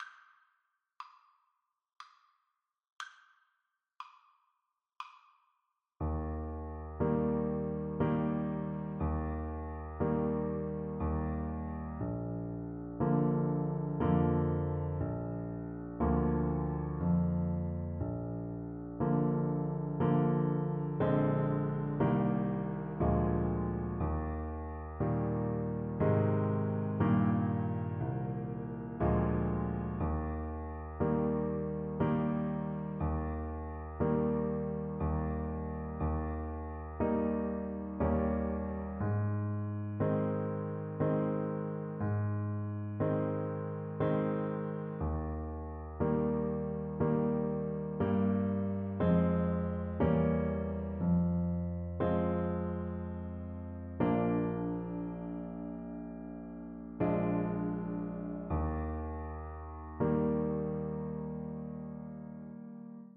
3/4 (View more 3/4 Music)
Bb4-C6
Moderato
Traditional (View more Traditional Trumpet Music)